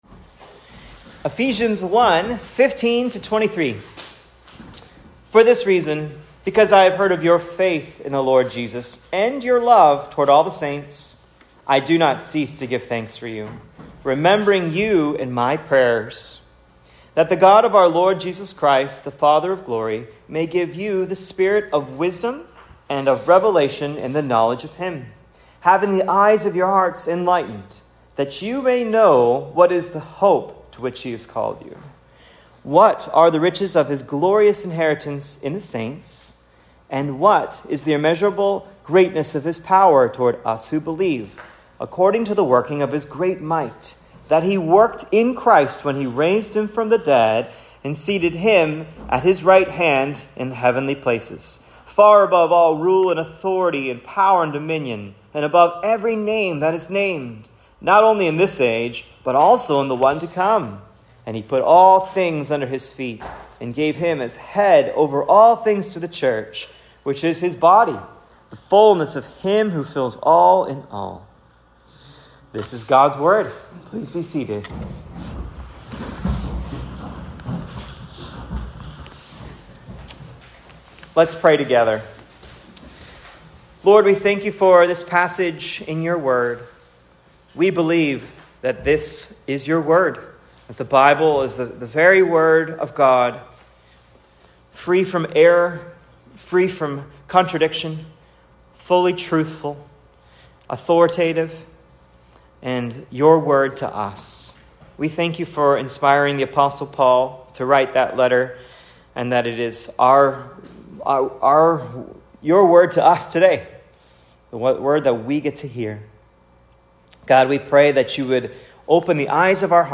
Eph 1:15-23 “Paul’s Prayer” SERMON OUTLINE
Here are notes for my sermon preached at Cross of Christ Fellowship on Ephesians 1:15-23 “Paul’s Prayer”